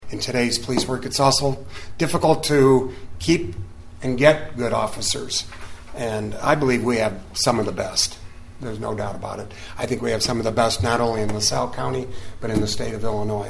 You can hear more about the police officers and what they did to earn the awards in this recording of Chief Doug Hayse speaking to the Oglesby City Council this week.